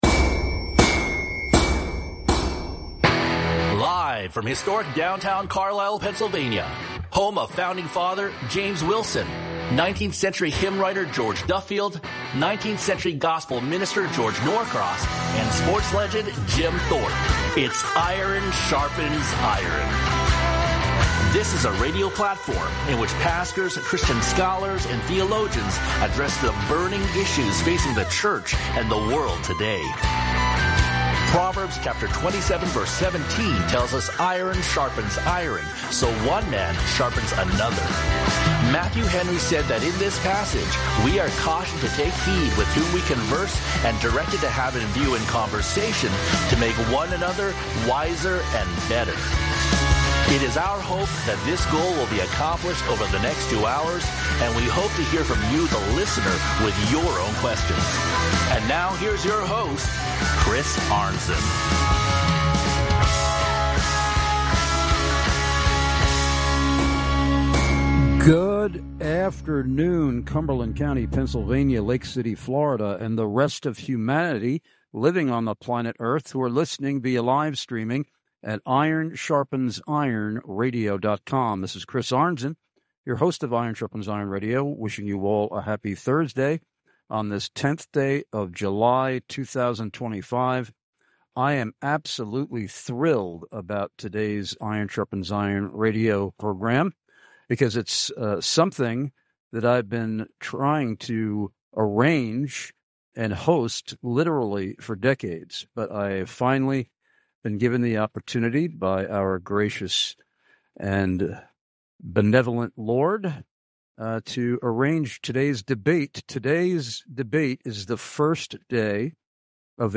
THE FIRST OF TWO LIVE DEBATES in JULY!!!!!!!!